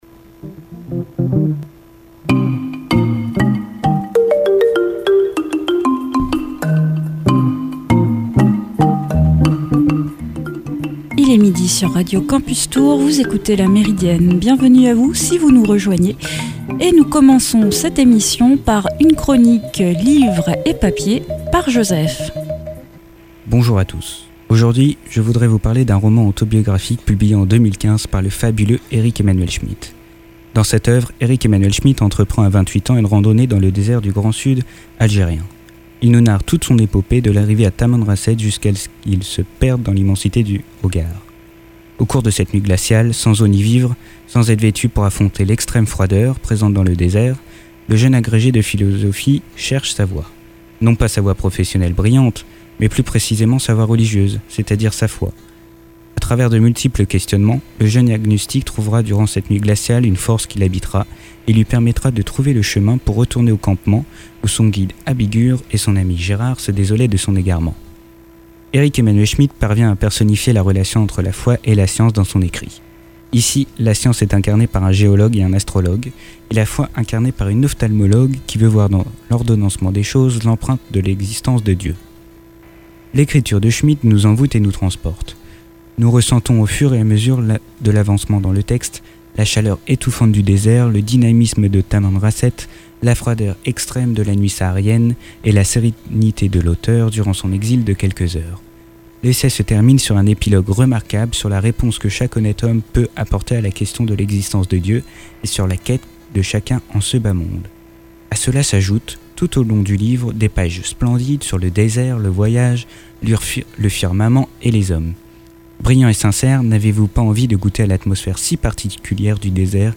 puis une interview de Corinne Maier, auteur du roman A La Conquête de l’homme rouge, paru aux éditions Anne Carrière, en cette rentrée littéraire.